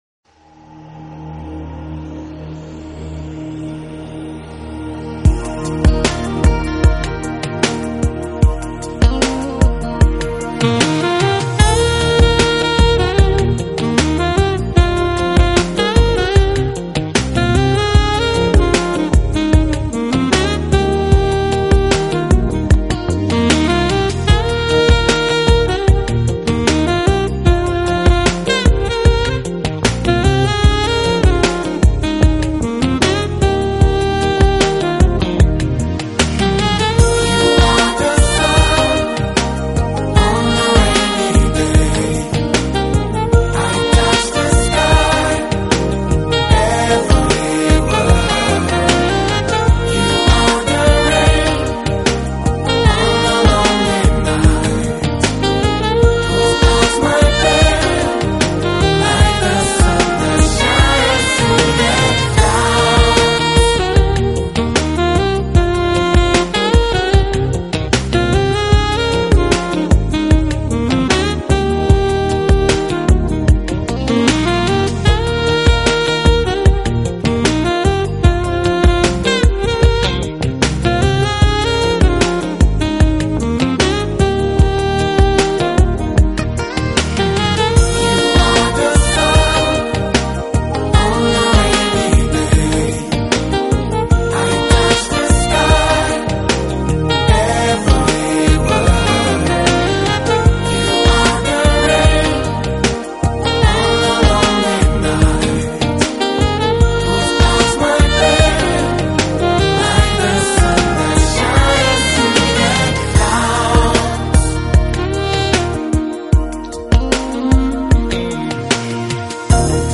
【爵士萨克斯】
Genre: Smooth Jazz